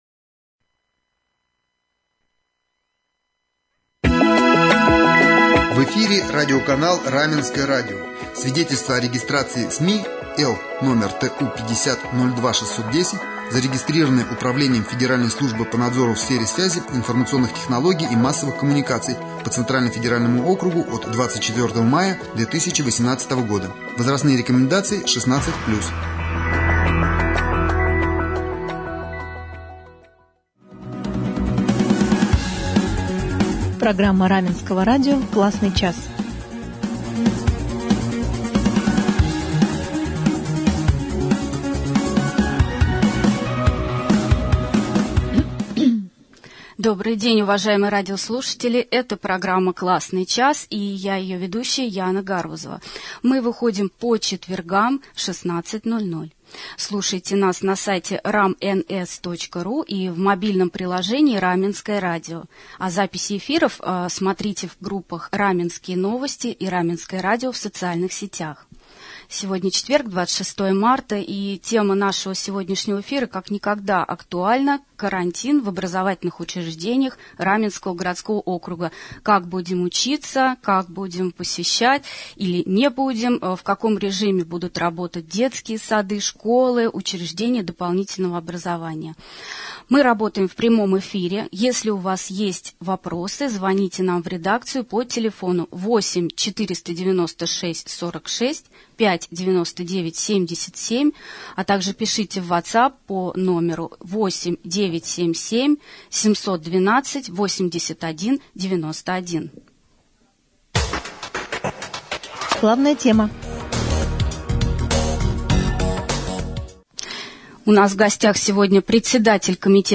Об этом мы поговорили с председателем Комитета по образованию Натальей Александровной Асеевой в программе «Классный час».